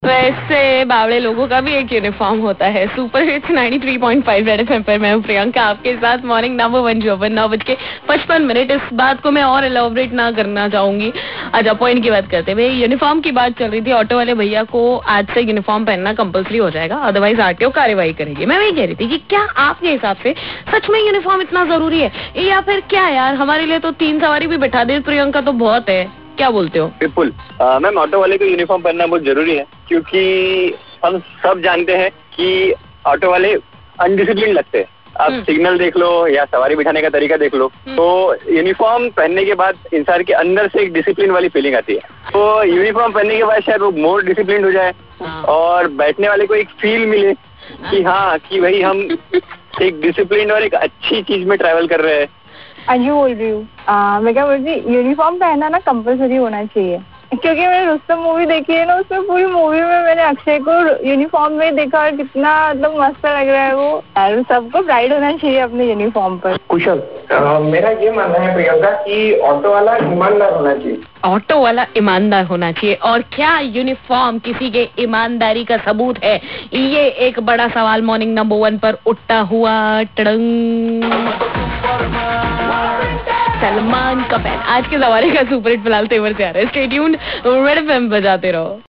talking about uniform with callers